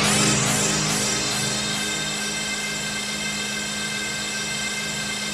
ATMOPAD12 -LR.wav